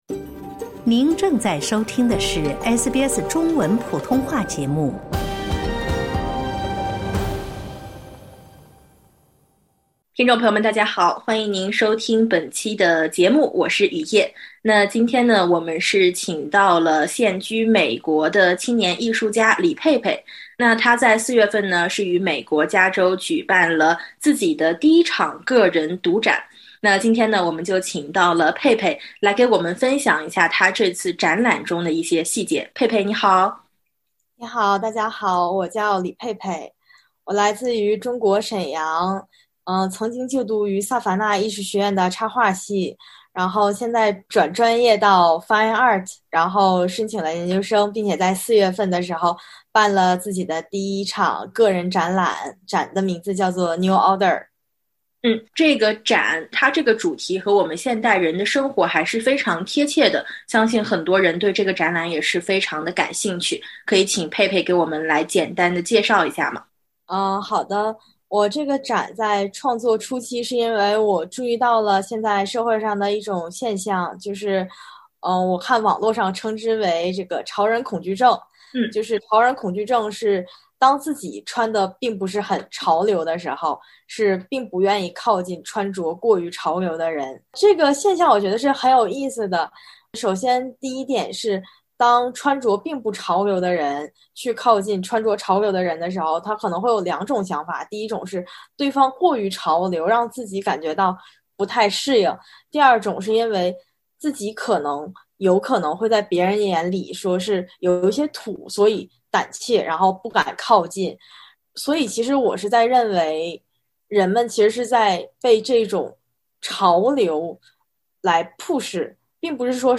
点击封面音频，收听完整采访 。